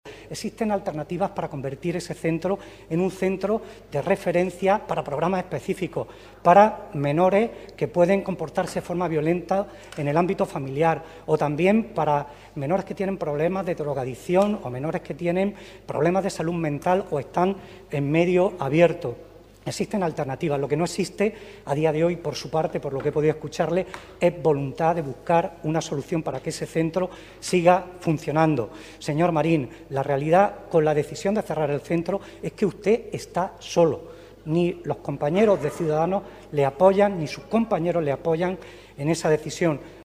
En su intervención ante el pleno del Parlamento de Andalucía, adonde el PSOE ha querido elevar esta cuestión, Sánchez Teruel ha asegurado que, con su “decisión política” de no renovar las plazas que hasta ahora tenía contratadas este centro para atender a 32 menores infractores, Marín “se queda solo”.
José Luis Sánchez Teruel, secretario general del PSOE de Almería